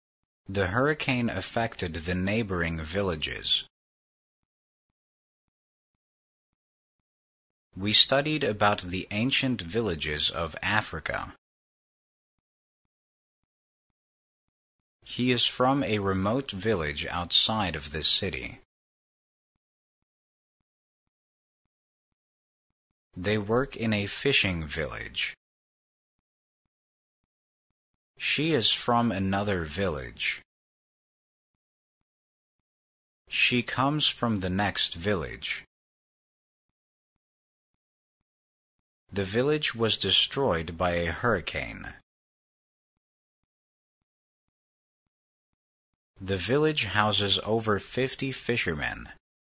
village-pause.mp3